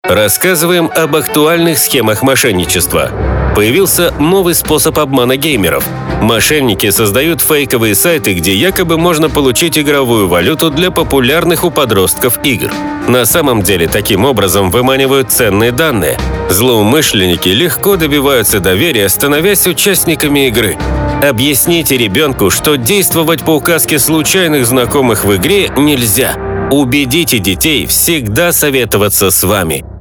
Социальная реклама, направленная на противодействие преступлениям, совершаемым с использованием информационно-телекоммуникационных технологий.